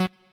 left-synth_chord66.ogg